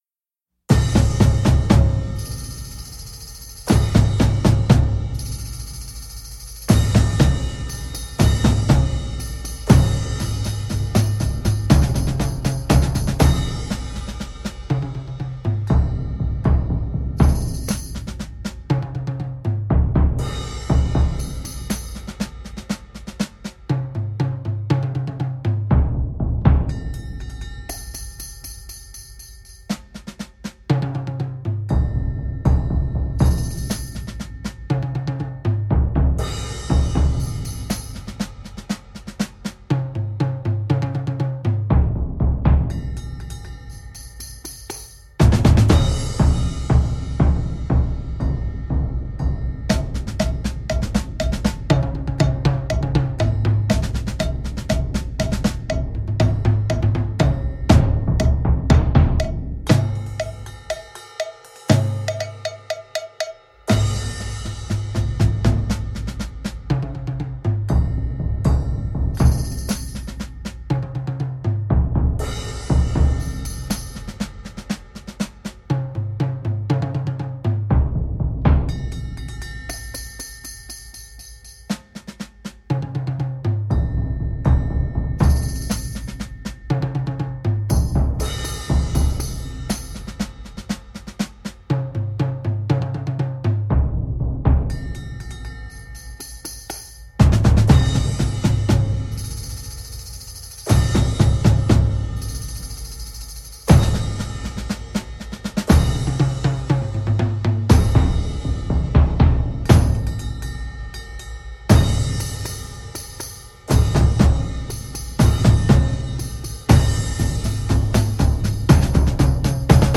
Players: 5-7
A Collection of 3 Grade I & II Ensembles